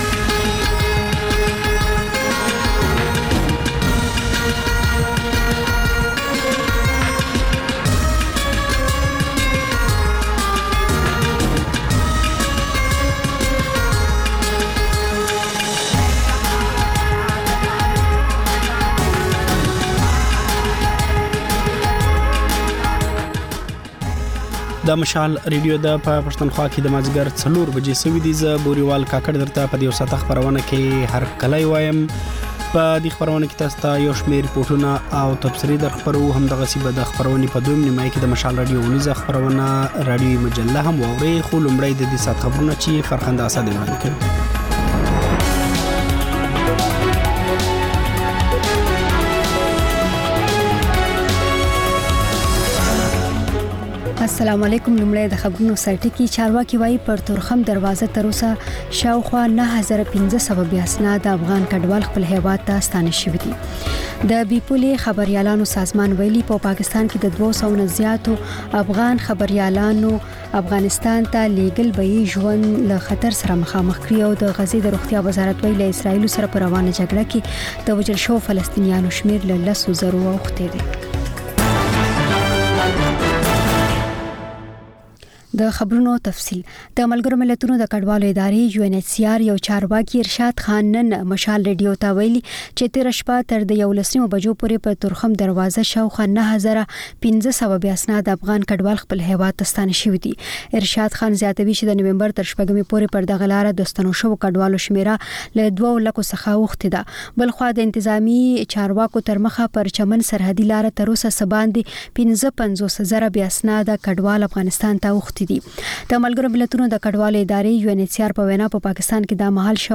د خپرونې پیل له خبرونو کېږي، ورسره اوونیزه خپرونه/خپرونې هم خپرېږي.